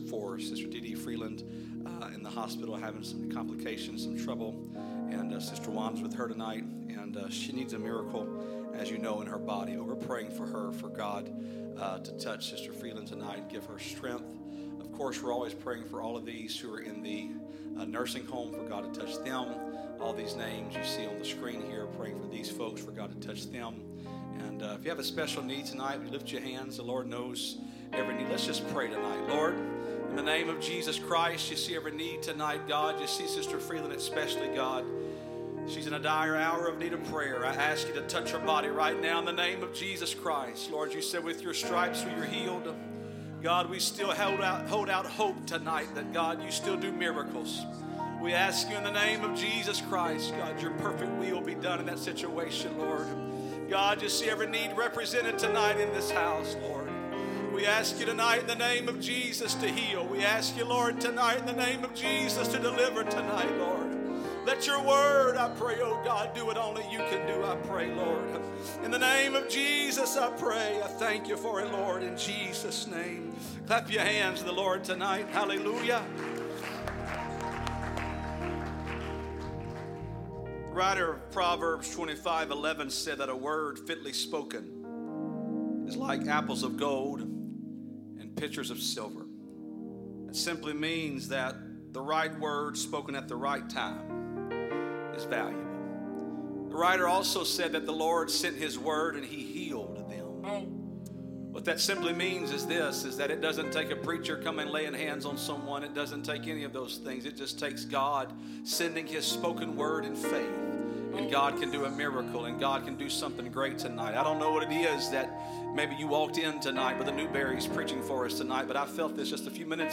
Sermons by United Pentecostal Church